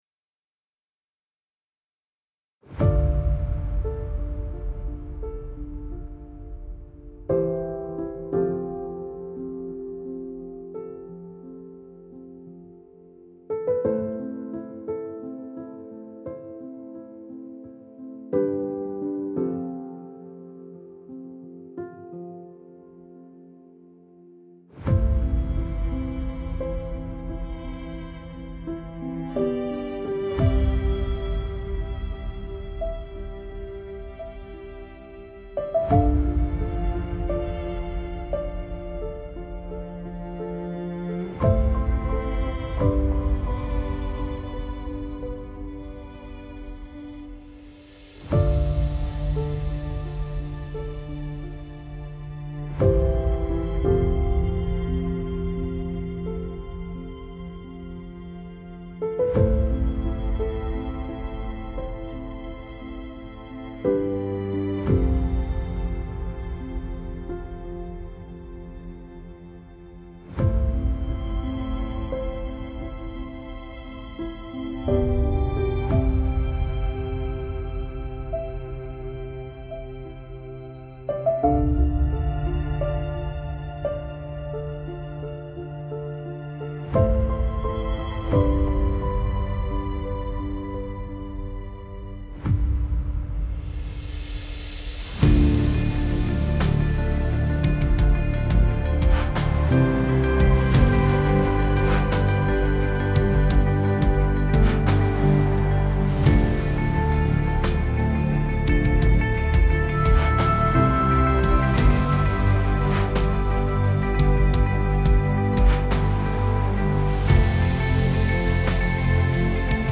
اپیک , الهام‌بخش , حماسی